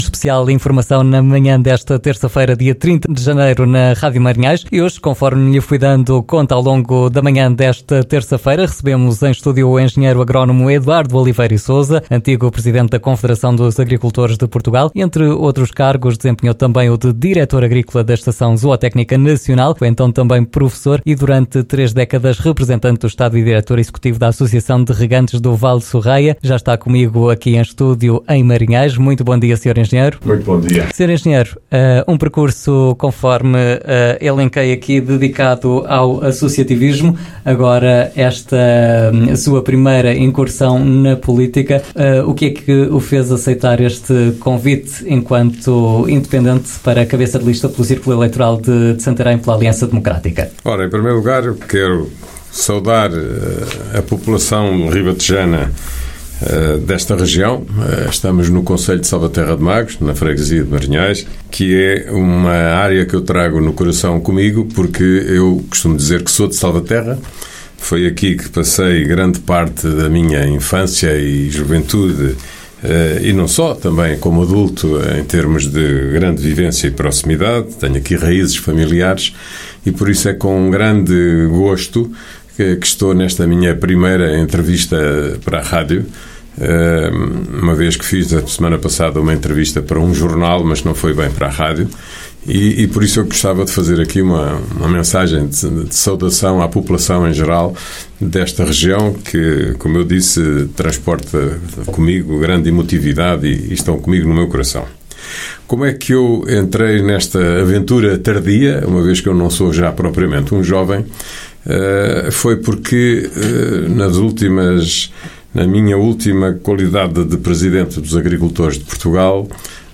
no Programa Informação e Música
entrevistou Eduardo Oliveira e Sousa, candidato pelo círculo eleitoral de Santarém às Legislativas de 10 de março.